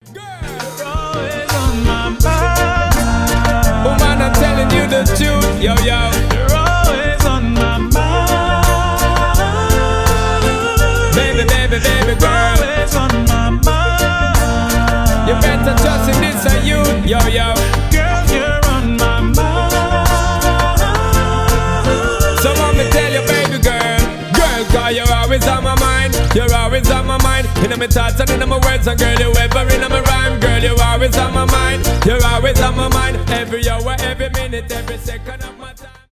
ремиксы , поп , заводные , ритмичные
регги